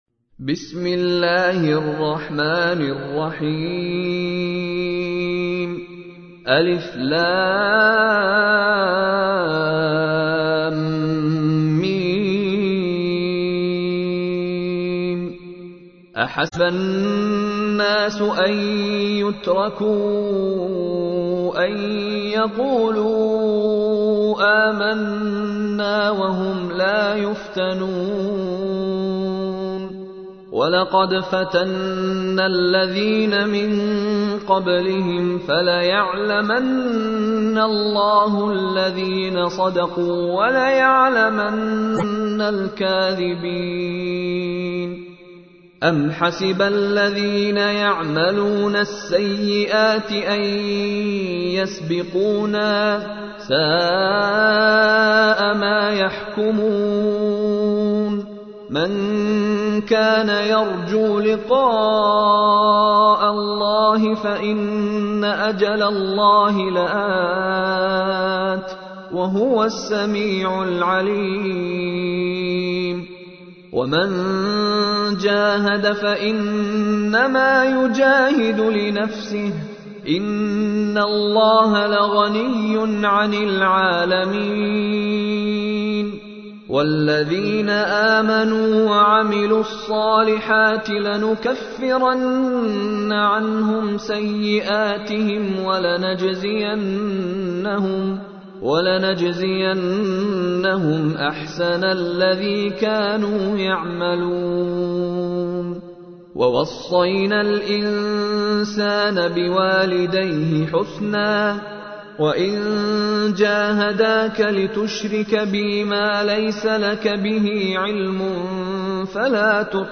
تحميل : 29. سورة العنكبوت / القارئ مشاري راشد العفاسي / القرآن الكريم / موقع يا حسين